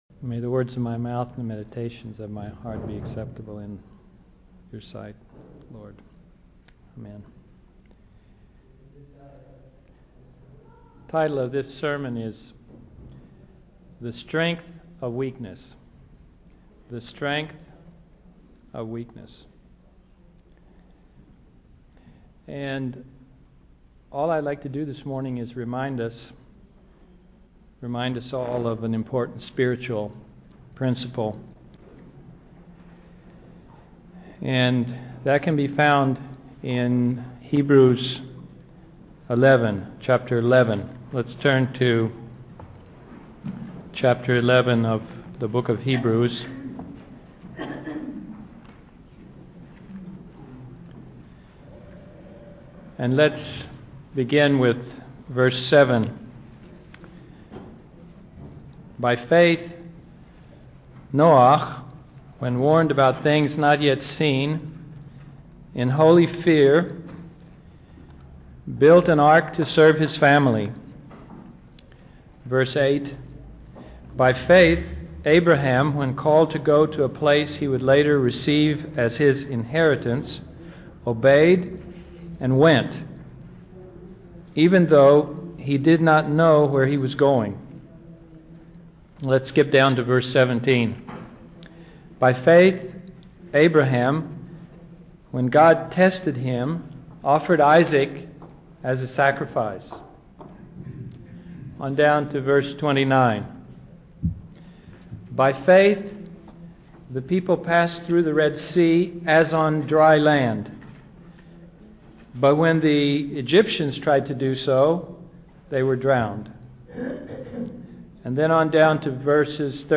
Download Bible studies and sermons recorded at the the Narkis Street Congretation where the late Rev. Dr. Robert L. Lindsey conducted his ministry in Jerusalem.